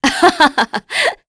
Glenwys-Vox_Happy2_kr.wav